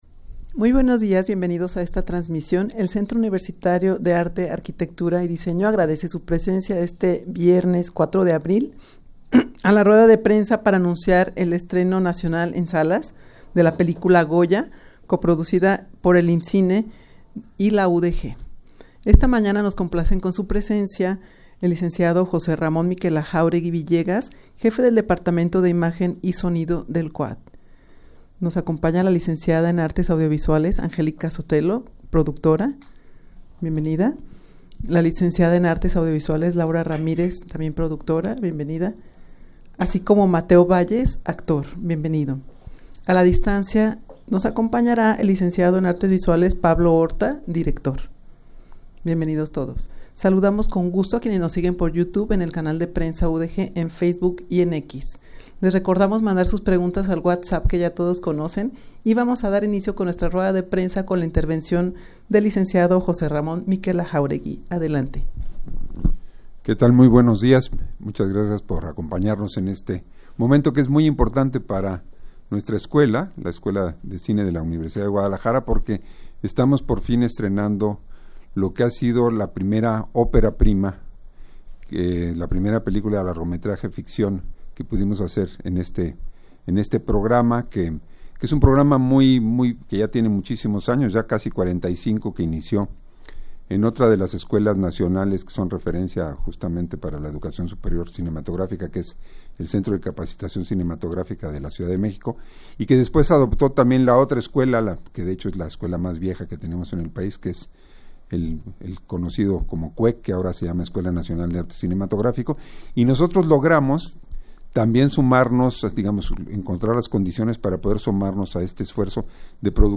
Audio de la Rueda de Prensa
rueda-de-prensa-para-anunciar-estreno-nacional-en-salas-de-la-pelicula-goya-coproducida-por-el-imcine-y-la-udeg.mp3